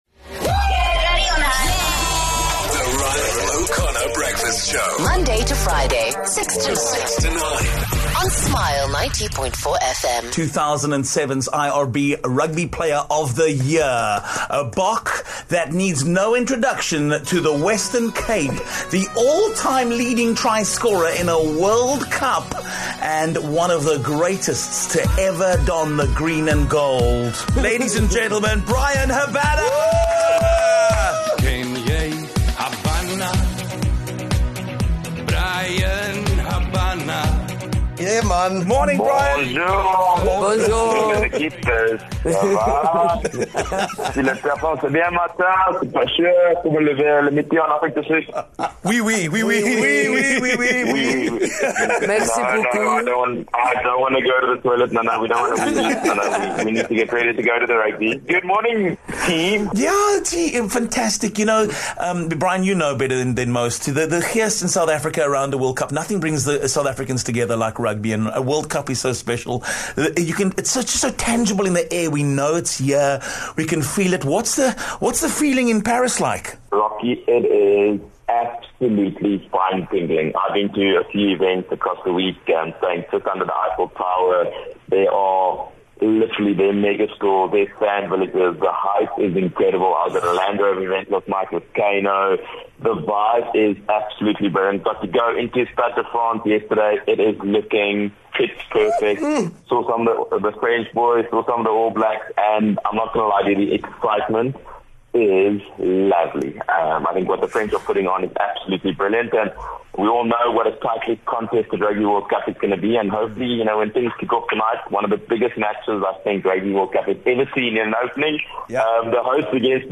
8 Sep Bryan Habana live from Paris on ROC Breakfast
One man who knows all about wearing the glory of the green and gold is Bryan Habana. The Springbok legend is in France for the Rugby World Cup and we gave him a call to hear what the vibe is like in Paris at the moment.